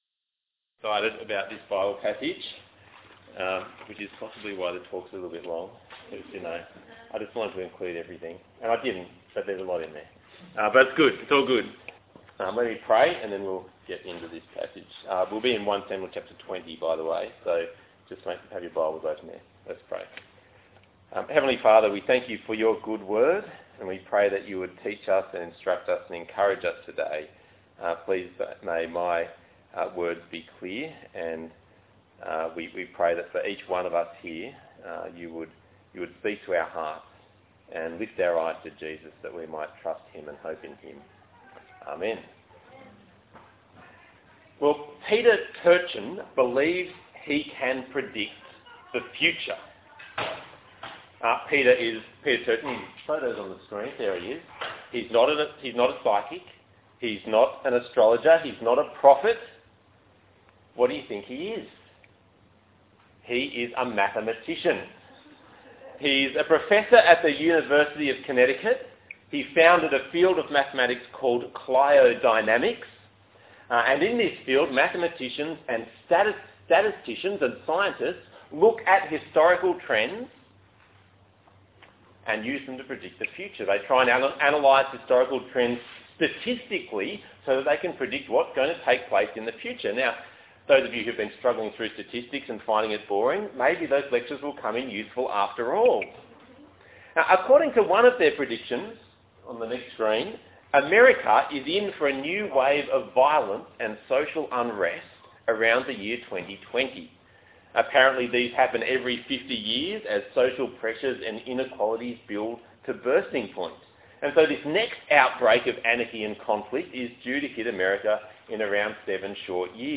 1 Samuel 20:1-42 Talk Type: Bible Talk « 1 Samuel 19